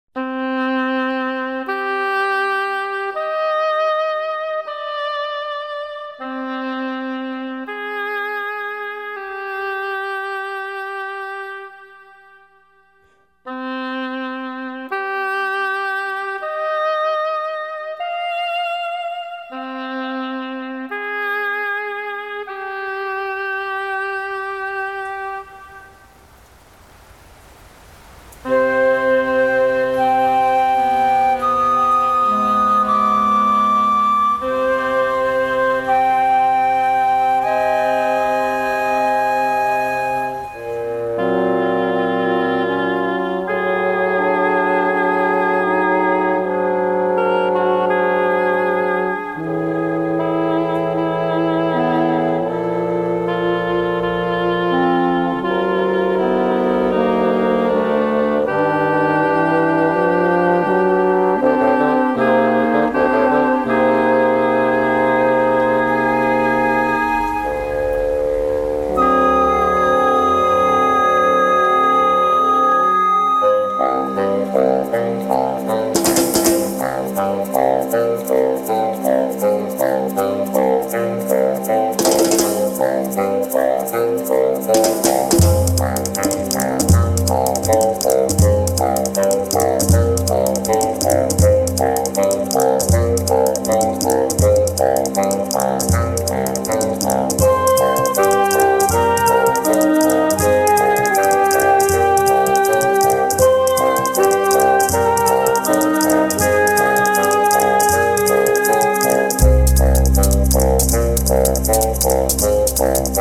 Instrumental Jazz
Música popular: instrumental y jazz